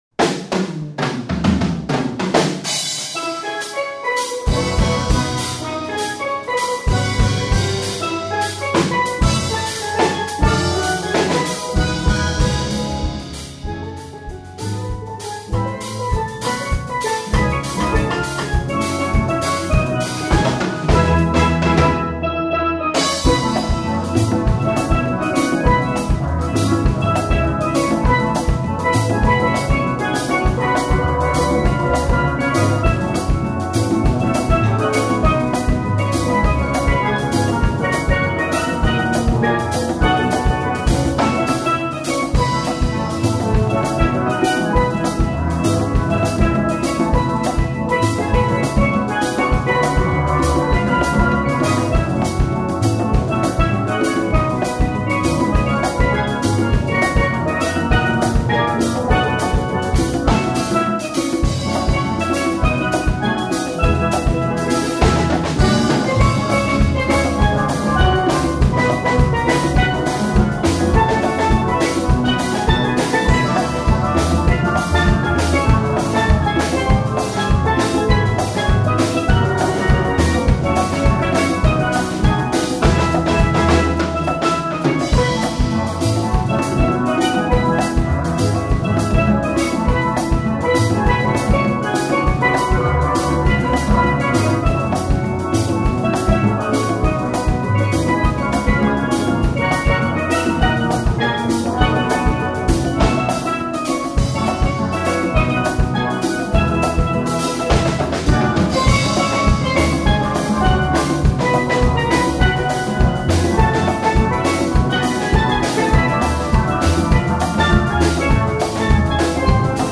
These songs are pefrormed by an amateur steel drum band